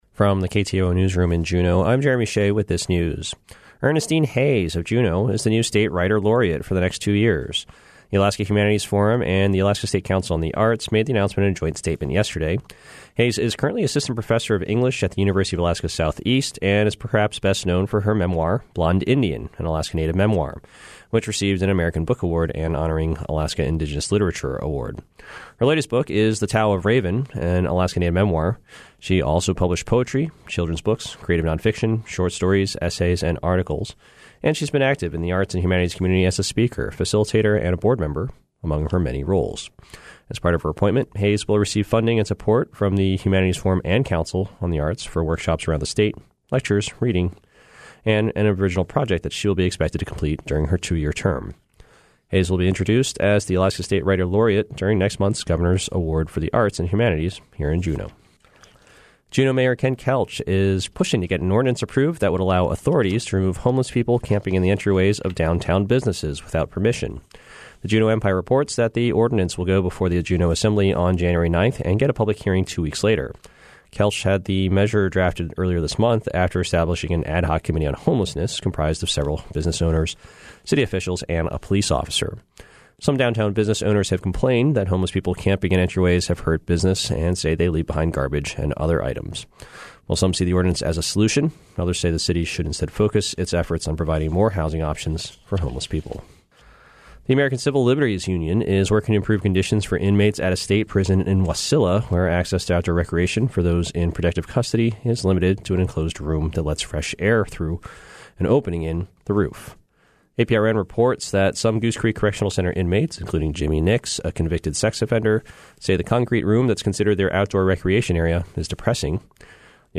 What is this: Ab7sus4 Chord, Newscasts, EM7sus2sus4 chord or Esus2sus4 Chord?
Newscasts